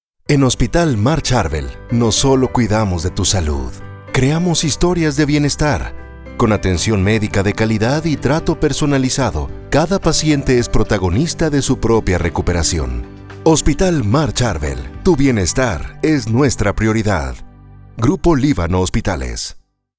Medical Narration
Styles: Warm, Elegant, Natural, Conversational, Sales Man, Corporate, Legal, Deep.
Equipment: Neumann TLM 103, Focusrite Scarlett, Aphex Channel, Source Connect
BaritoneBassDeepLowVery Low